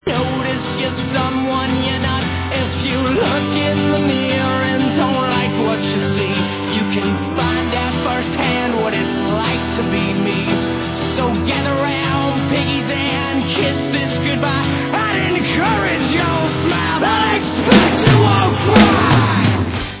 Rock/Hardcore